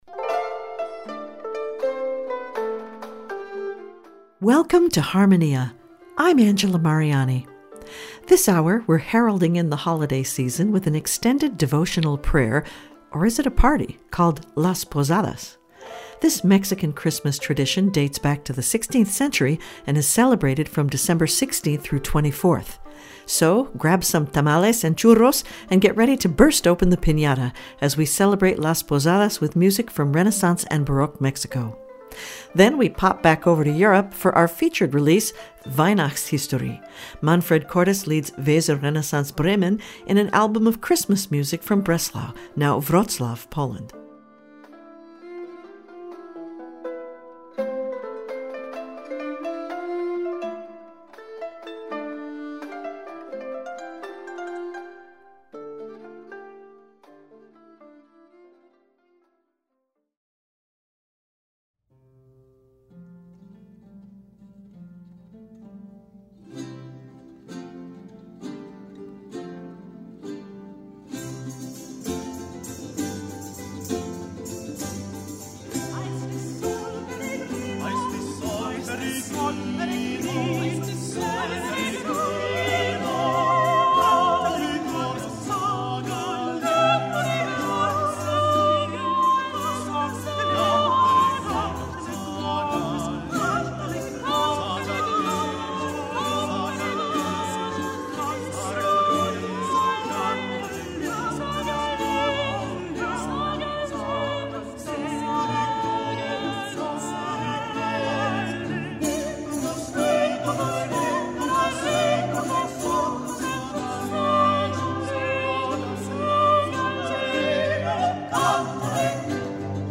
radio program
Get ready to burst open the piñata as Harmonia celebrates the traditional Latin American Christmas pageant, Las Posadas, with Renaissance- and Baroque- style music from Mexico.